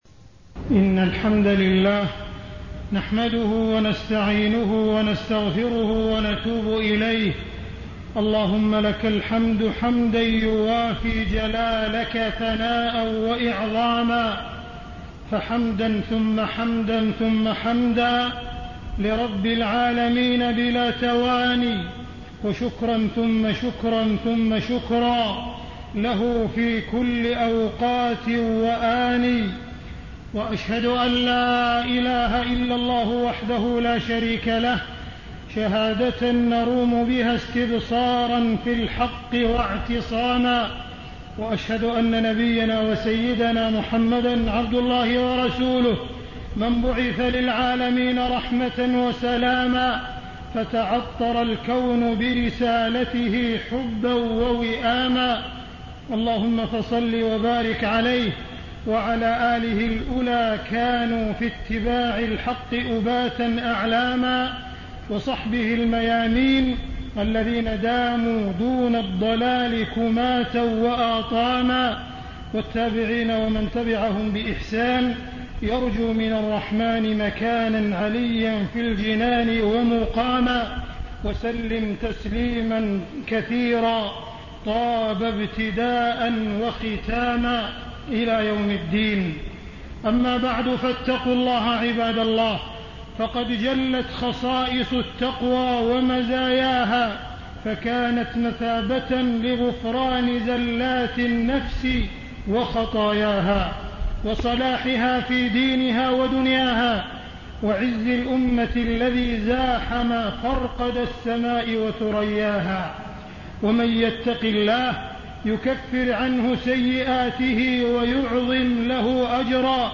تاريخ النشر ١٦ شوال ١٤٣٤ هـ المكان: المسجد الحرام الشيخ: معالي الشيخ أ.د. عبدالرحمن بن عبدالعزيز السديس معالي الشيخ أ.د. عبدالرحمن بن عبدالعزيز السديس أهمية الوحدة بين المسلمين